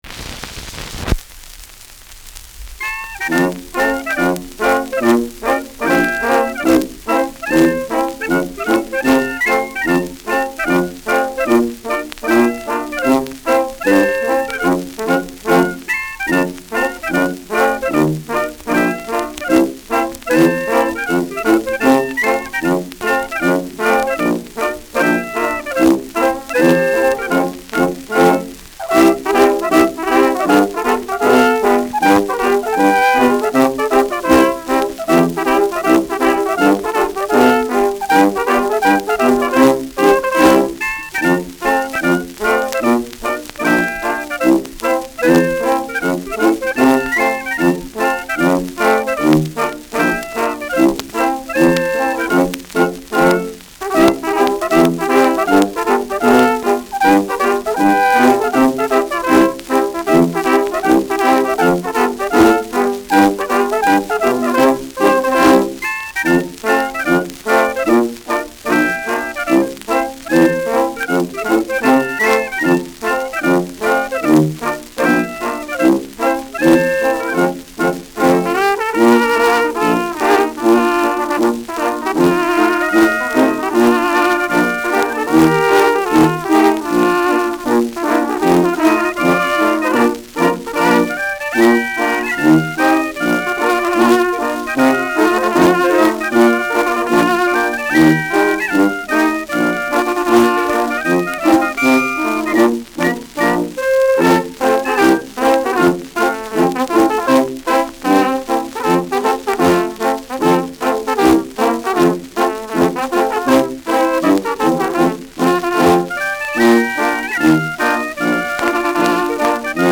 Schellackplatte
Gelegentlich leichtes Knacken
[Nürnberg] (Aufnahmeort)